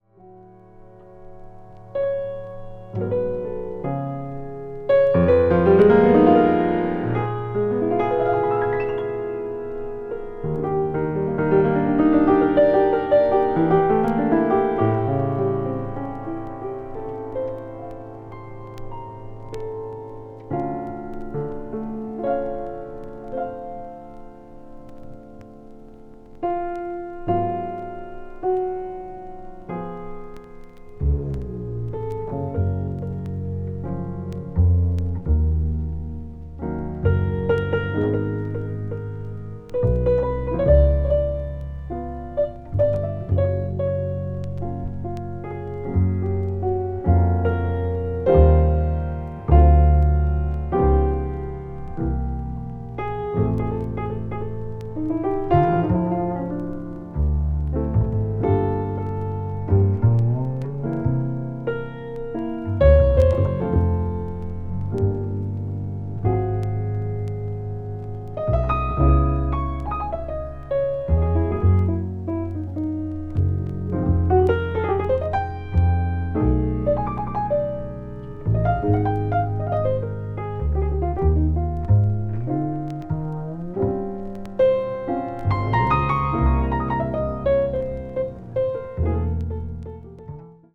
contemporary jazz   modal jazz